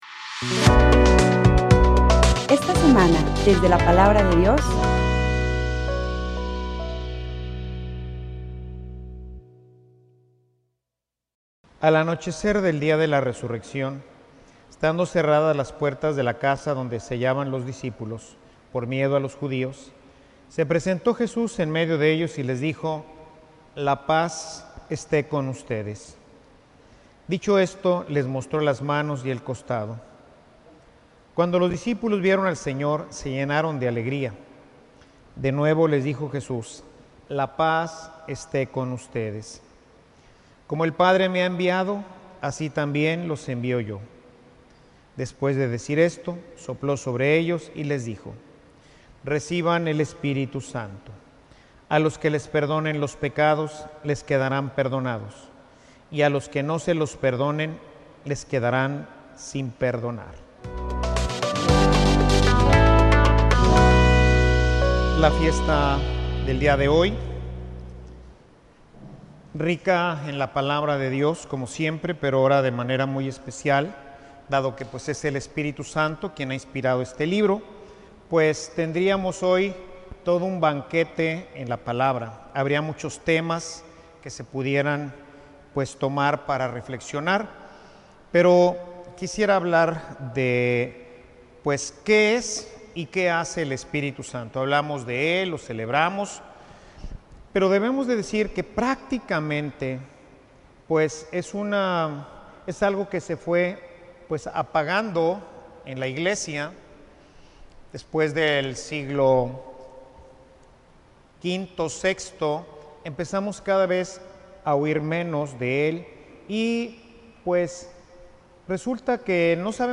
Homilia_quien_es_y_que_hace_el_espiritu_santo.mp3